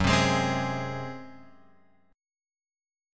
F#sus2b5 chord {2 3 x 1 1 4} chord